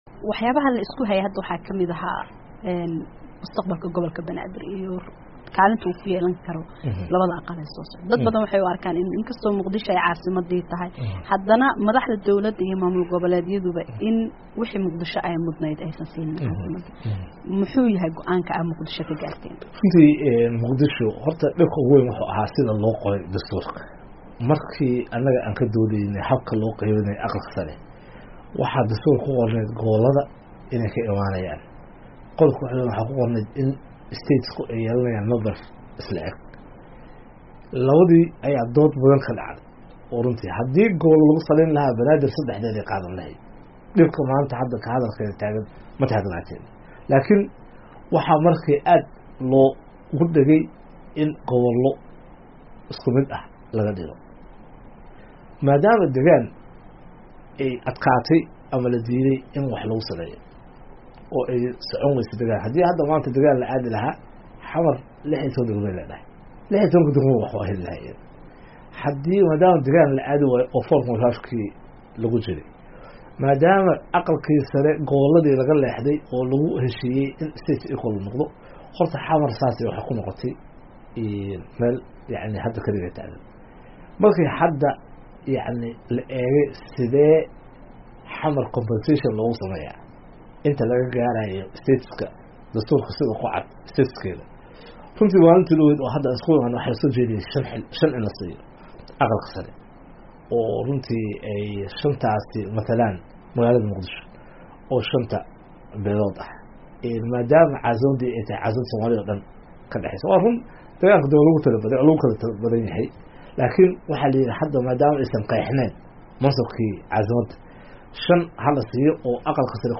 Wareysiga Cumar Cabdirashiid, Qeybta Labaad